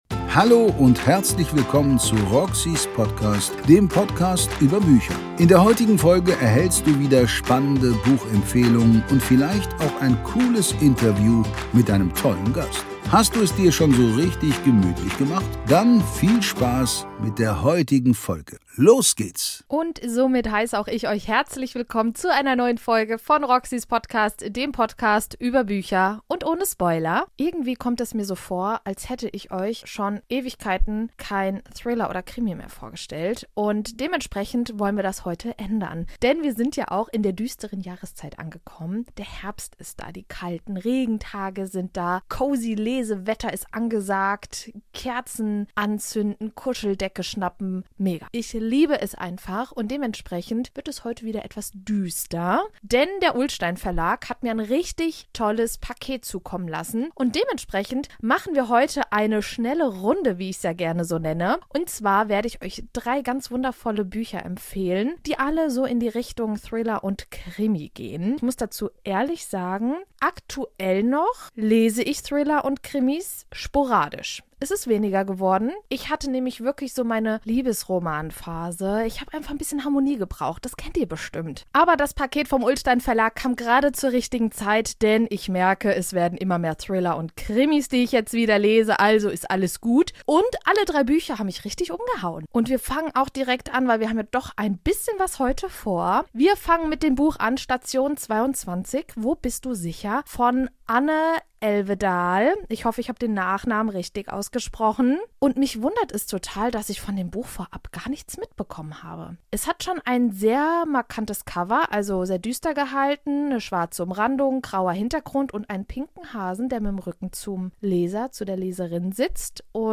Dem Podcast über Bücher. Seit März 2019 stelle ich jeden Sonntag ein neues Buch vor und lese euch auch ein paar Seiten vor.